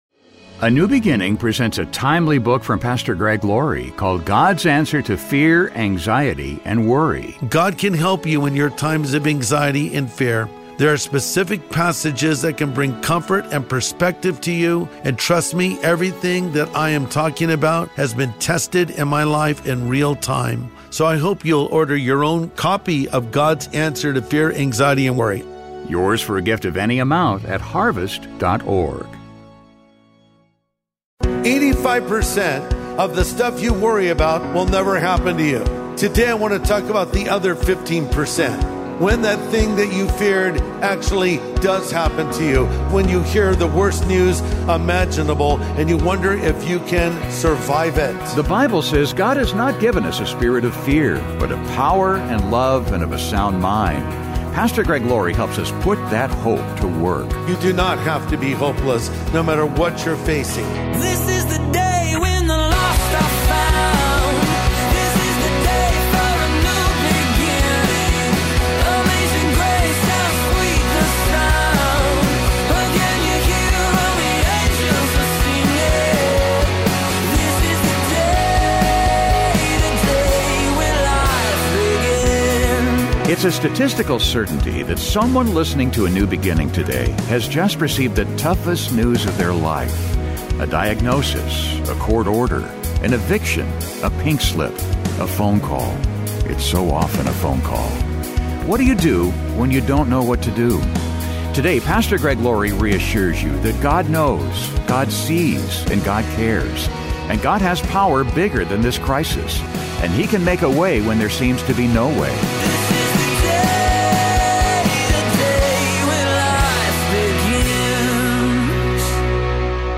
What do you do when you don't know what to do? Today, Pastor Greg Laurie reassures you that God knows, God sees, and God cares. And God has power that's bigger than this crisis.